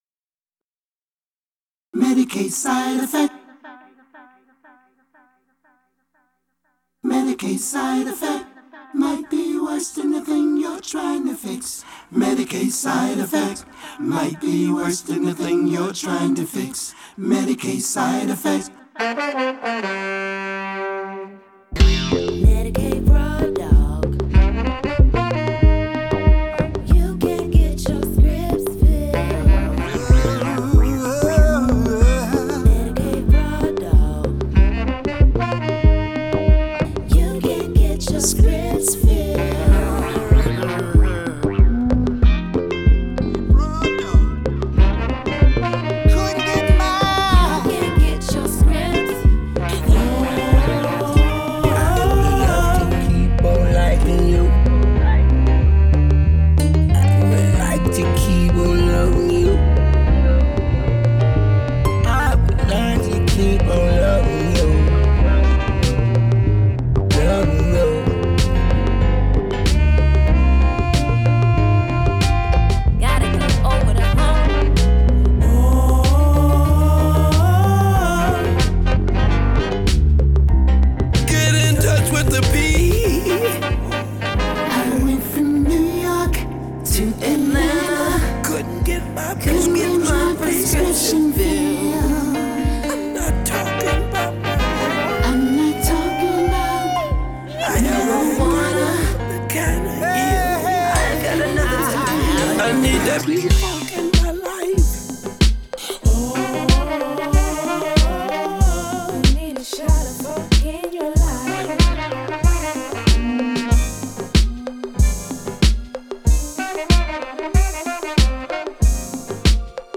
It’s not your mama’s p-funk.